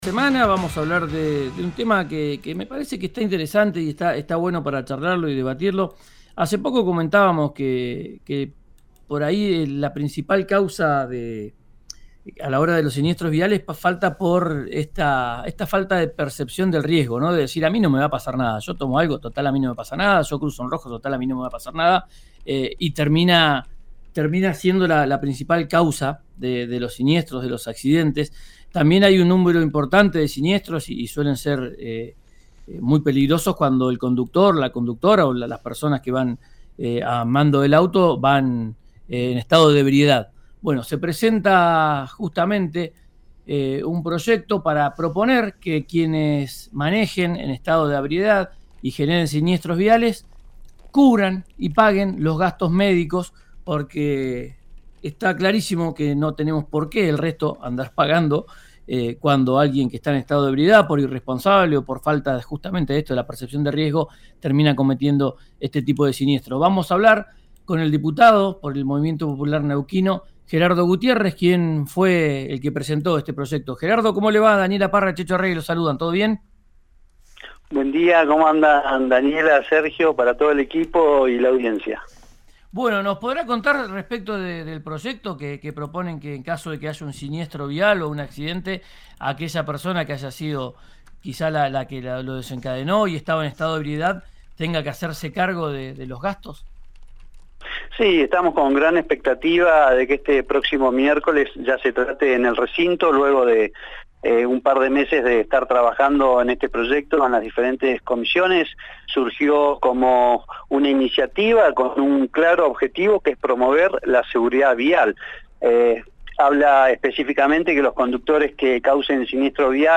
El diputado Gerardo Gutiérrez, autor del proyecto, dio más detalles en RÍO NEGRO RADIO.
Escuchá a Gerardo Gutiérrez, diputado provincial por el MPN, en RÍO NEGRO RADIO: